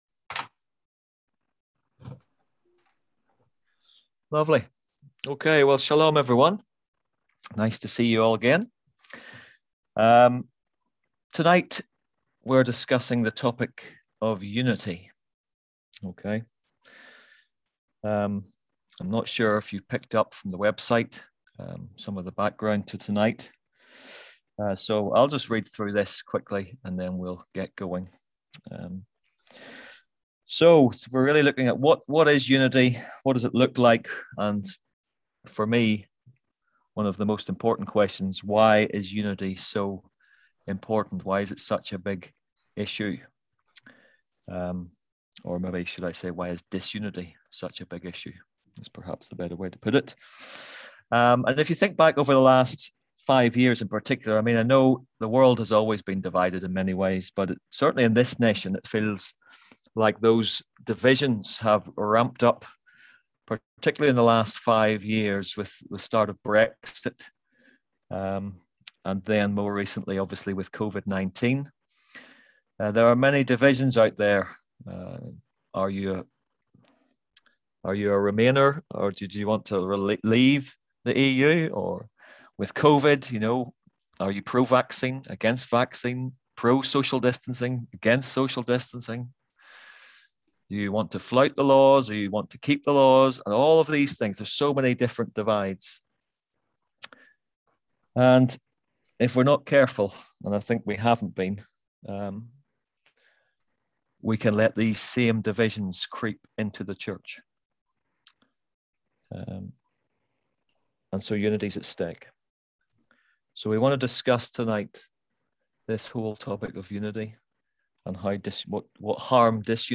Click here to listen to the full story of our F14 conference, ‘when Christians disagree’.